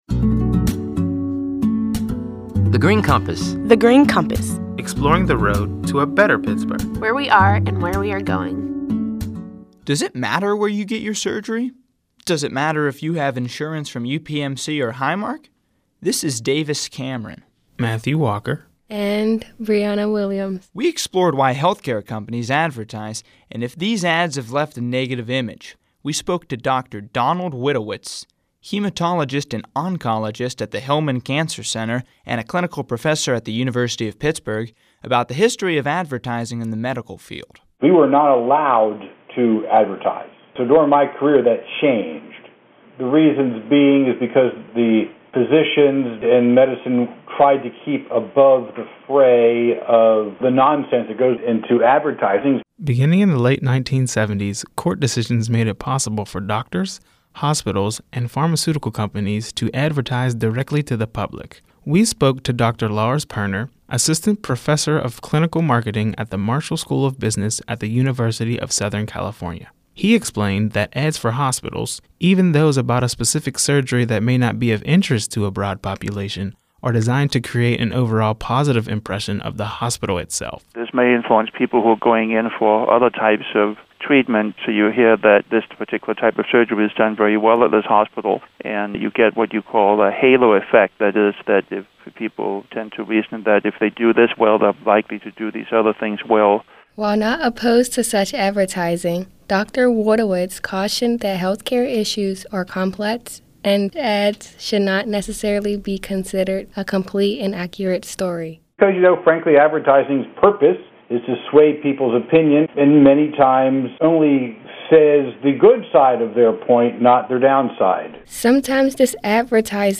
In July 2014, thirty-two recent high-school graduates created these radio features while serving as Summer Interns at The Heinz Endowments.